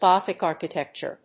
Góthic árchitecture
音節Gòthic árchitecture